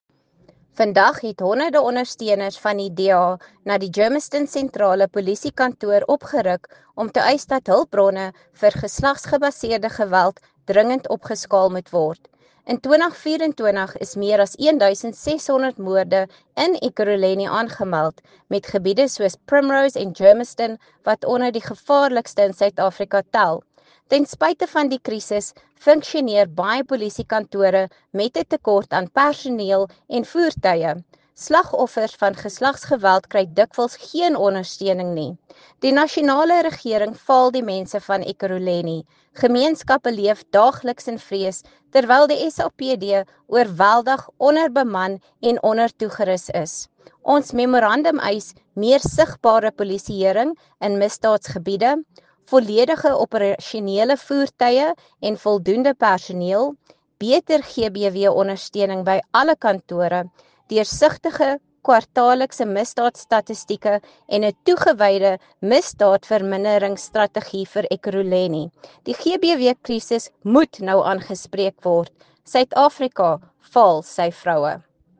here, and an Afrikaans soundbite
Lisa-Schickerling-LP_AFR_Geslagsgeweld-ruk-handuit-in-Ekurhuleni.mp3